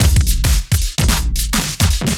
OTG_DuoSwingMixC_110b.wav